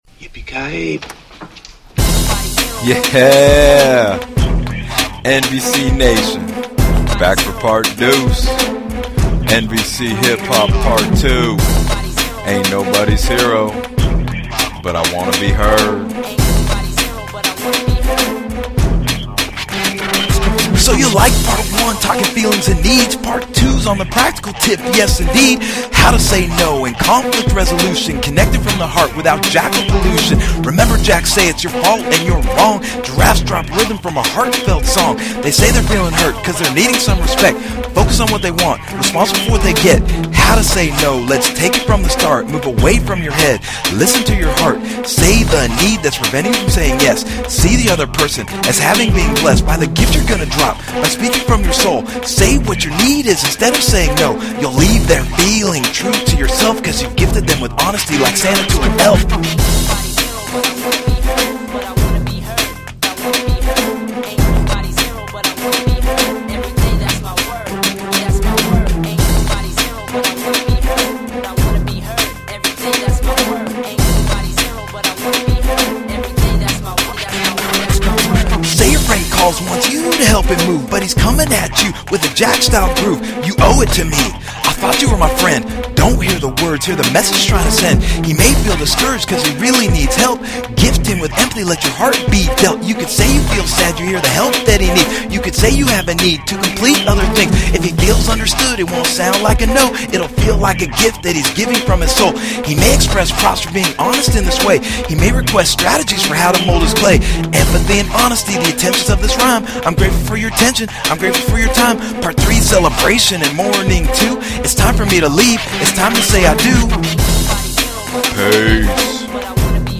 NVC Hip Hop ‘Yippee Ki Yay’